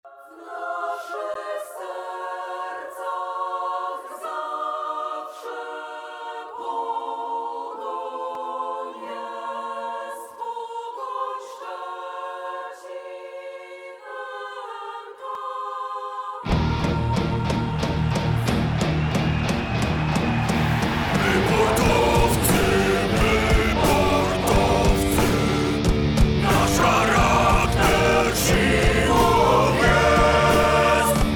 w aranżacji na chór i zespół metalowy